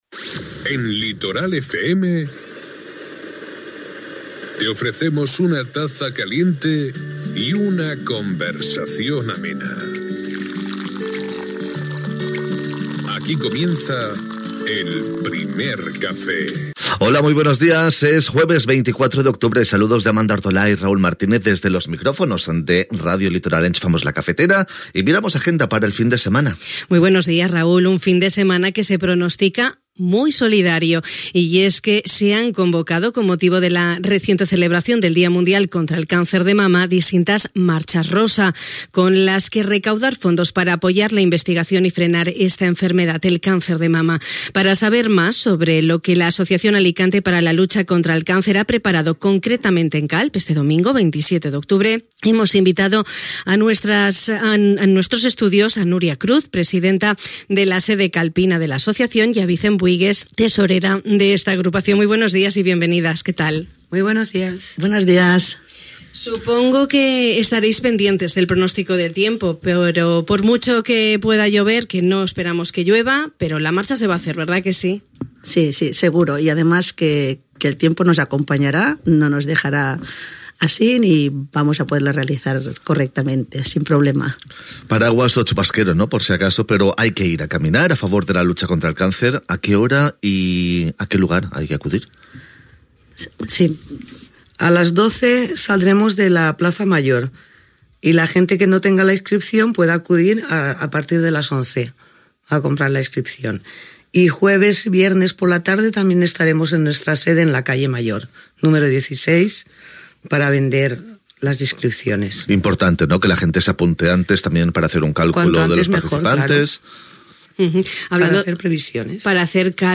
Aquesta matí, al Primer Cafè de Ràdio Litoral, hem convidat als nostres estudis